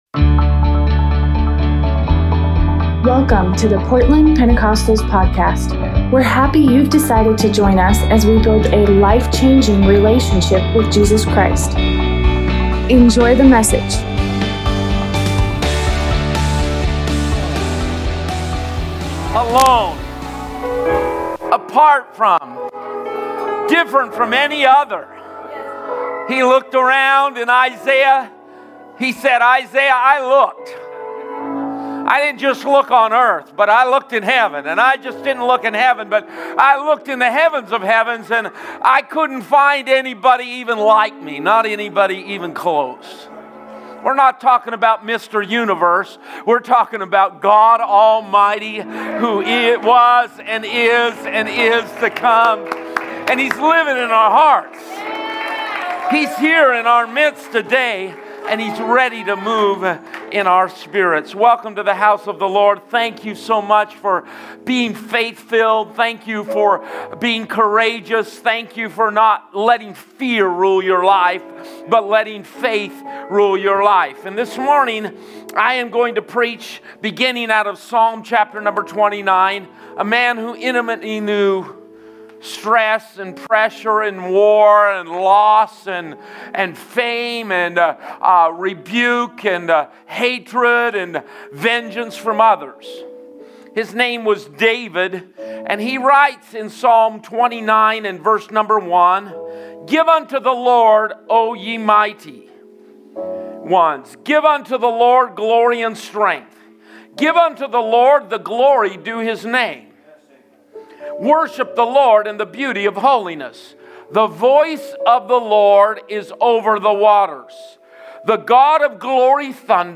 Sunday sermon